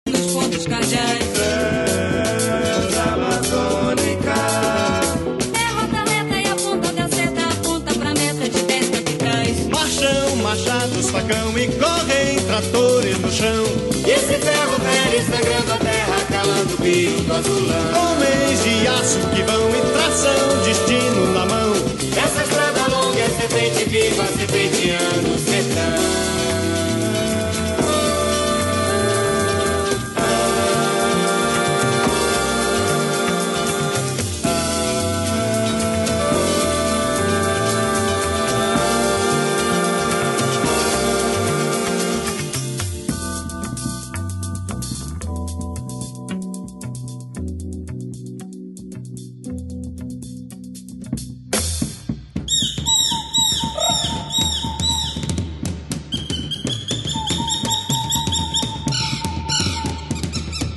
Brazilian jazz fusion